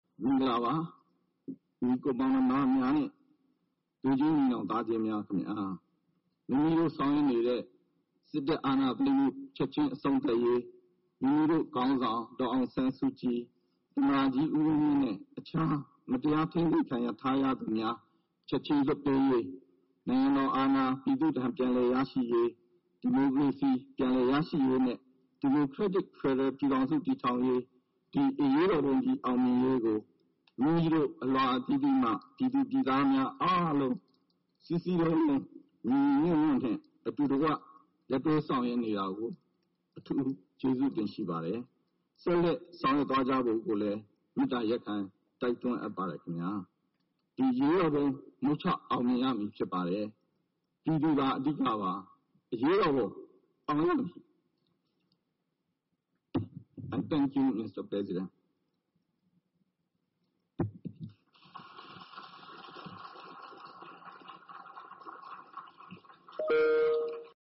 ကုလသမဂ္ဂဆိုင်ရာ မြန်မာသံအမတ်ကြီး ဦးကျော်မိုးထွန်းက ဒီကနေ့ကျင်းပတဲ့ ကုလသမဂ္ဂ အထွေထွေညီလာခံမှာ NLD အစိုးရကိုသာ ဆက်ပြီး ကိုယ်စားပြုကြောင်း ပြောသွားပါတယ်။ သံအမတ်ကြီးရဲ့ မြန်မာလိုပြောဆိုချက် အပြည့်အစုံကတော့ -
သံအမတျကွီး ဦးကြောျမိုးထှနျးရဲ့ မွနျမာလိုပွောဆိုခကြျအပွည့ျအစုံကို နားဆငျနိုငျပါတယျ။